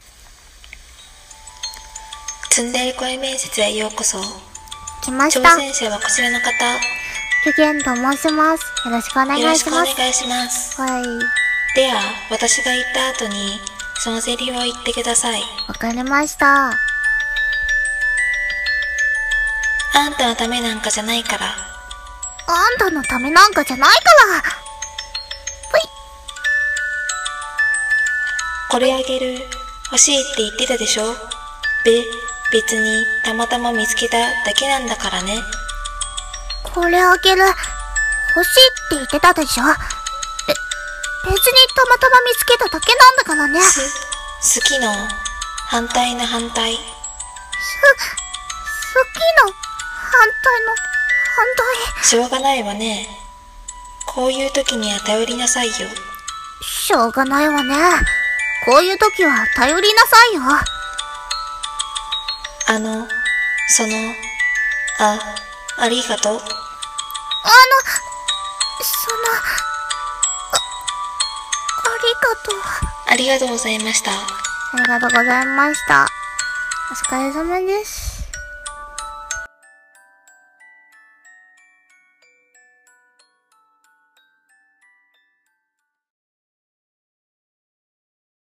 ツンデレ声面接